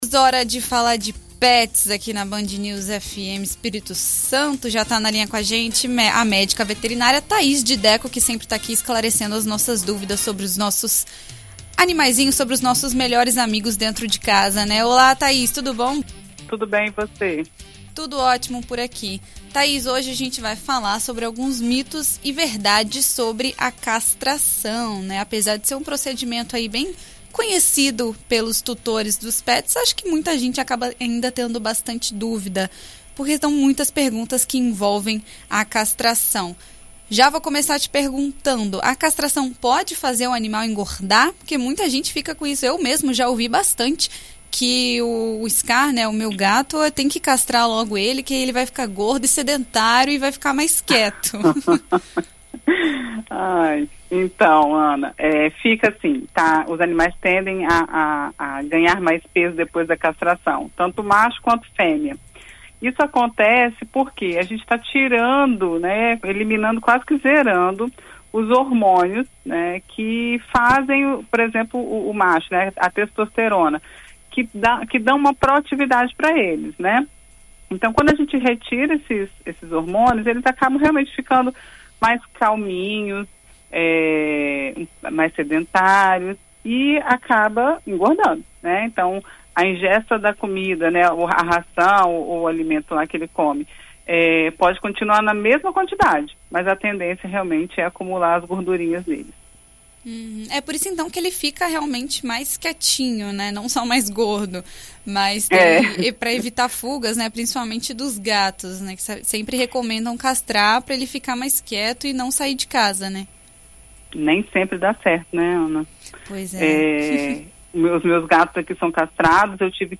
Em entrevista à BandNews FM ES nesta quarta-feira